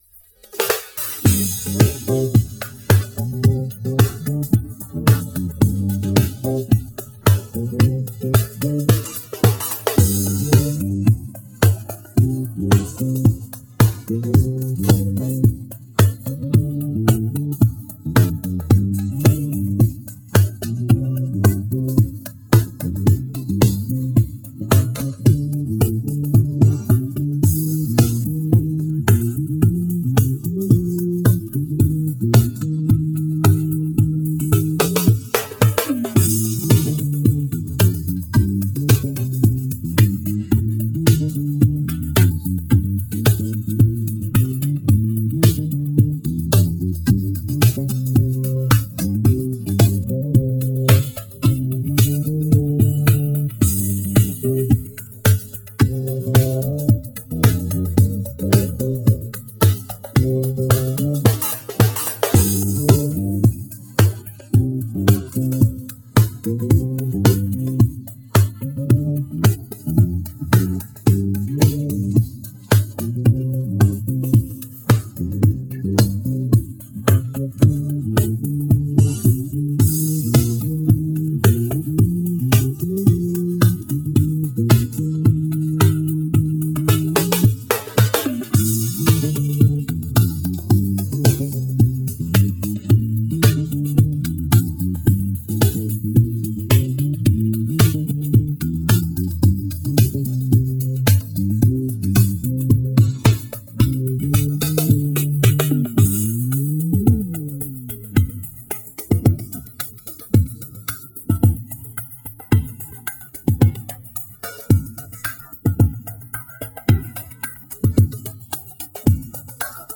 Музыка конечно не моя...но в моём исполнении https